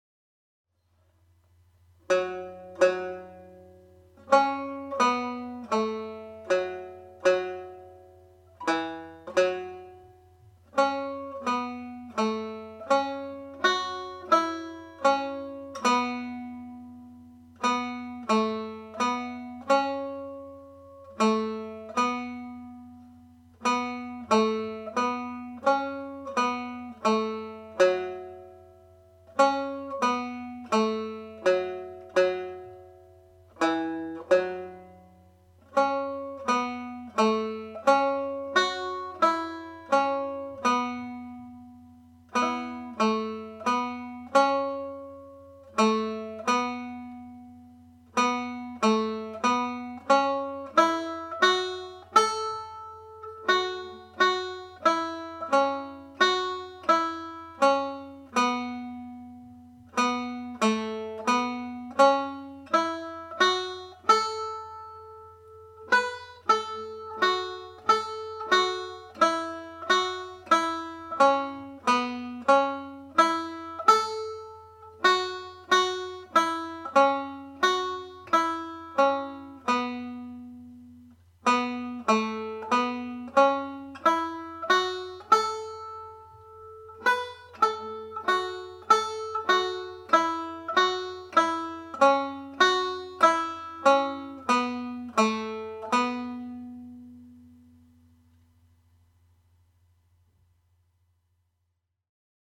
Slip Jig (F sharp Minor)
played slowly